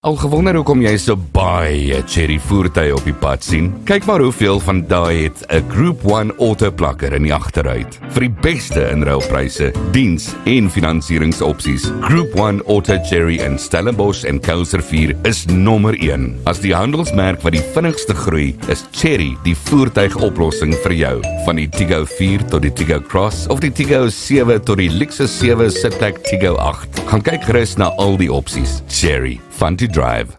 Professional voiceover work for commercials, promos, podcasts, and more.
Group_1_Auto_Chery_Advert_-_Voiceover.mp3